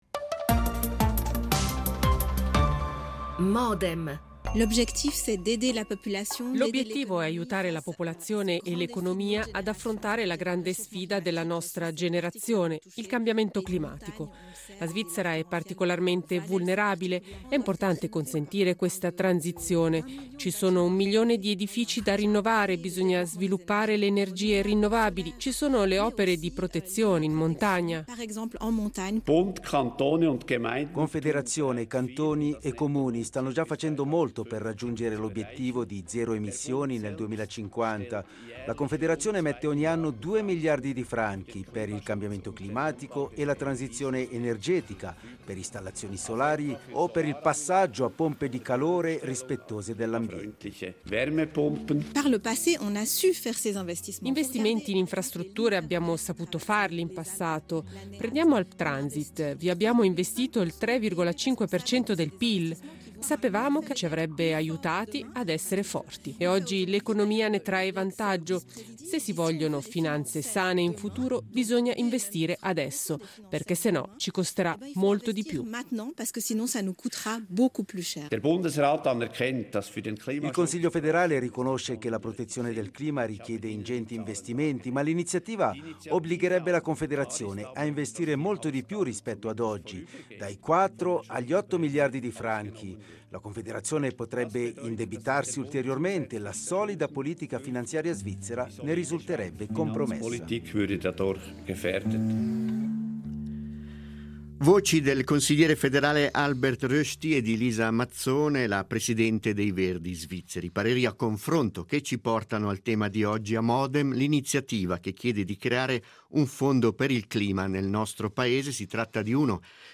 Il dibattito in vista della votazione dell’8 marzo tra favorevoli e contrari grigionesi all’iniziativa